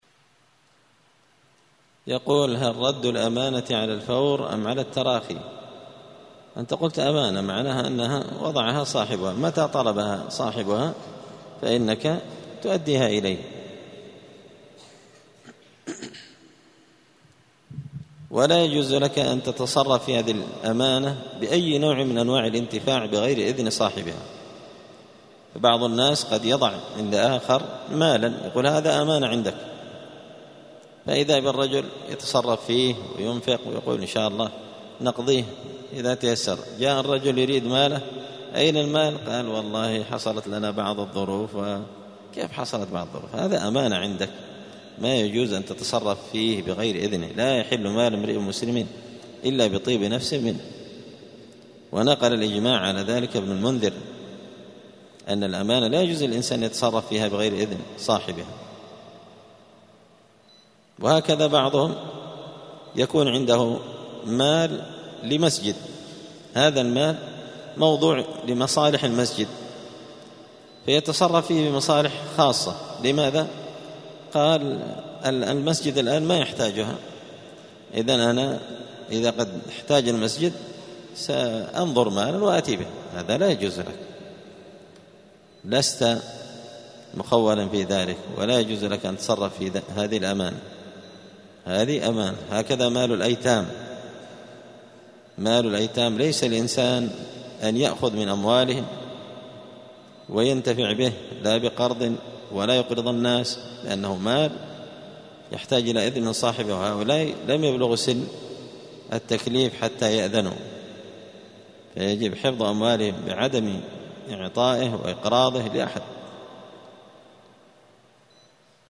دار الحديث بمسجد الفرقان ـ قشن ـ المهرة ـ اليمنمساء الاثنين 29 صفر 1446هـــ